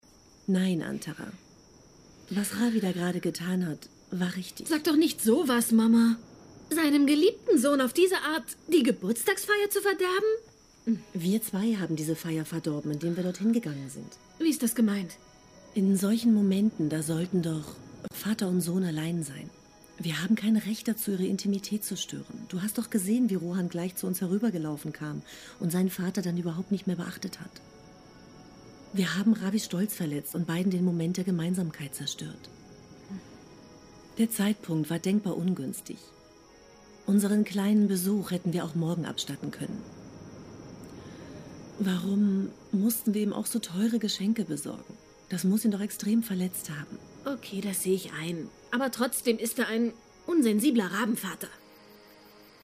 spanische und deutsche Profi Sprecherin. Sehr variable Stimme, auch Zeichentrick, und Kinderstimmen
Sprechprobe: Industrie (Muttersprache):
german / spanish female voice over artist